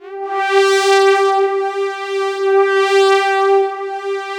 110 PAD G4-L.wav